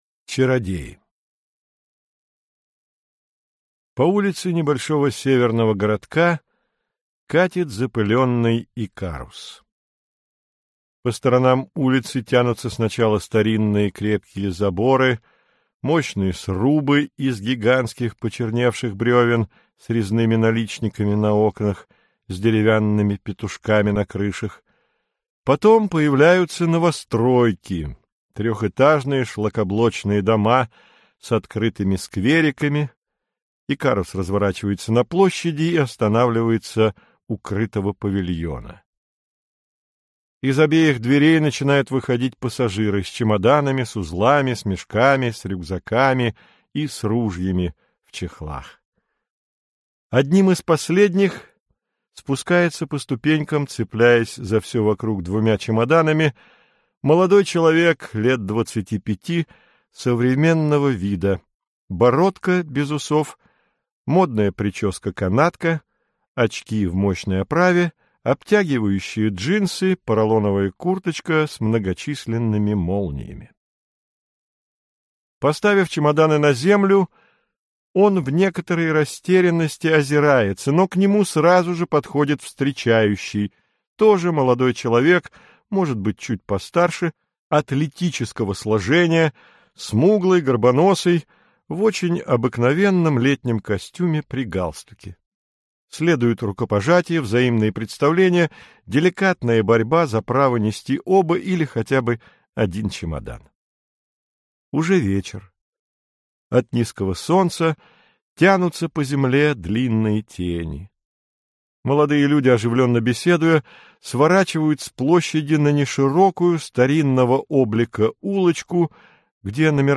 Аудиокнига Чародеи | Библиотека аудиокниг